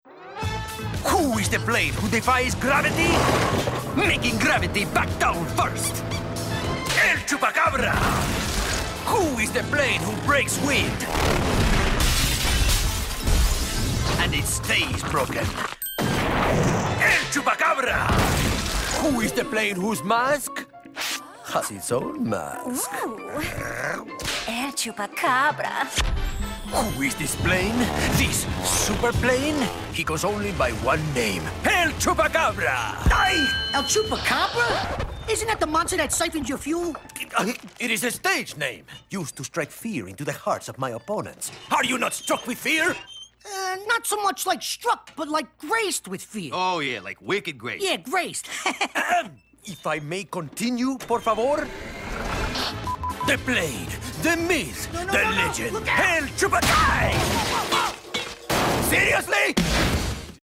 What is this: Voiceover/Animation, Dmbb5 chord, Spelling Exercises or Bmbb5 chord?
Voiceover/Animation